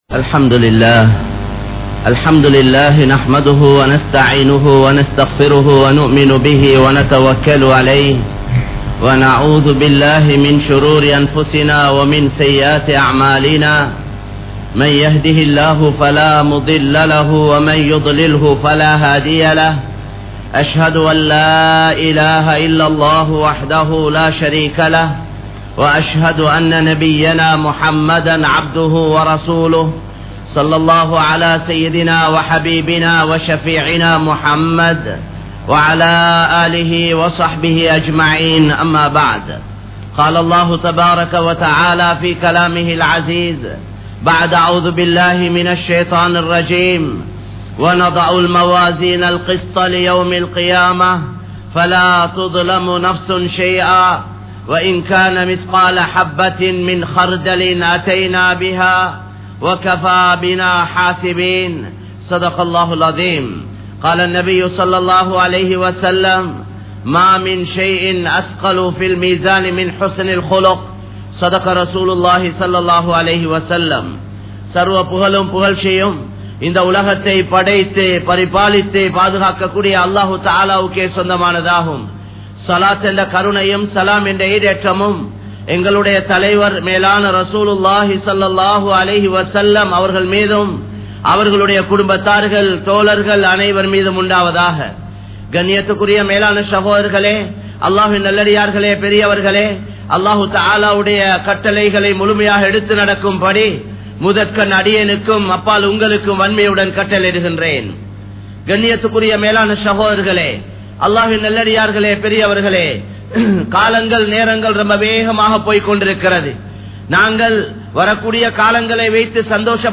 Ramalan Emmil Eatpaduththiya Maattram Enna? (ரமழான் எம்மில் ஏற்படுத்திய மாற்றம் என்ன?) | Audio Bayans | All Ceylon Muslim Youth Community | Addalaichenai
Kollupitty Jumua Masjith